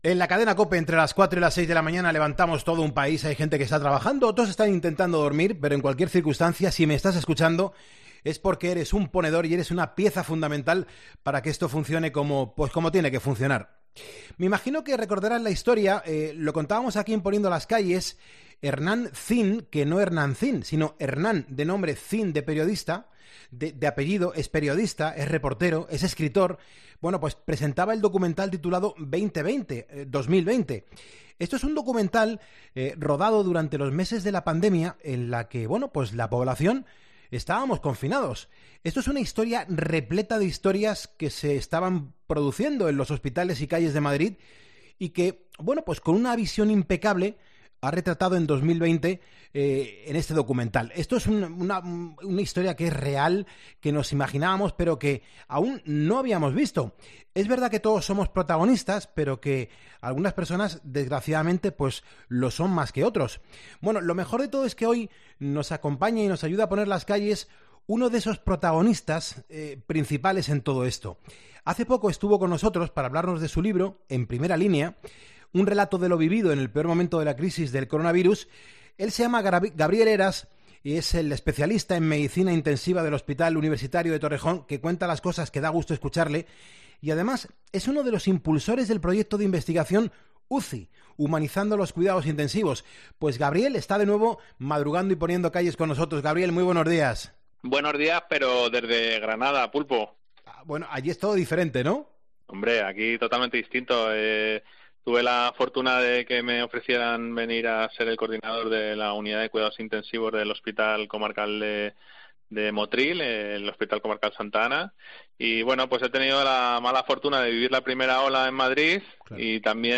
AUDIO: Un documental repleto de historias que se estaban produciendo en los hospitales y calles de Madrid.